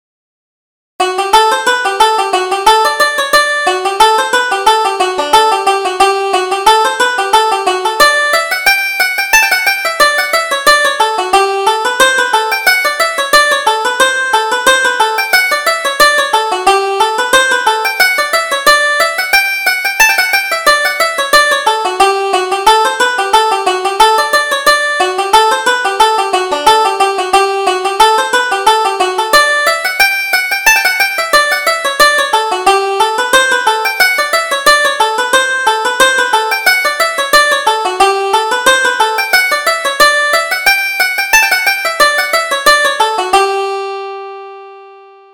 Reel: Johnny Allen's Reel